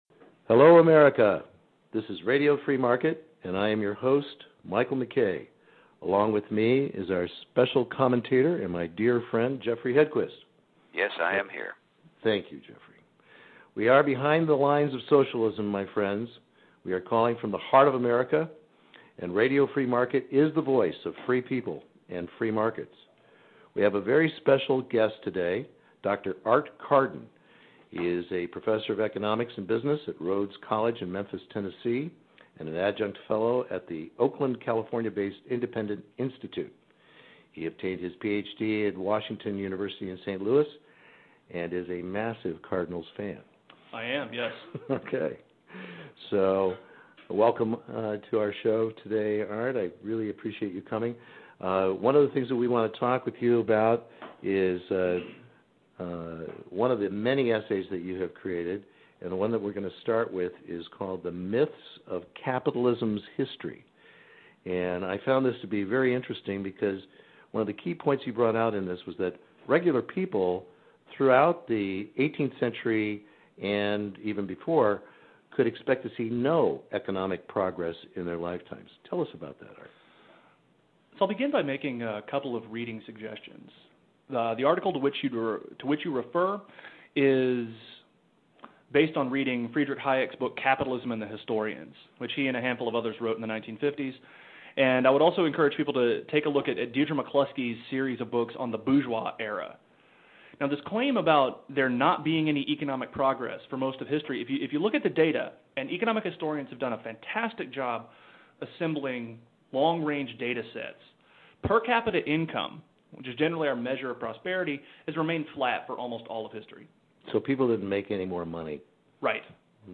In this fascinating and very entertaining show we discuss: The myths of Capitalism’s History How the Serfs became Consumers, and Our Unlimited Economic Potential – if only we don’t screw it up. This fun and lively conversation addresses issues that are relevant to all Consumers – and why we must correct for everyone the confusion as to What Capitalism IS and Is Not!